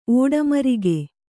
♪ ōḍamarige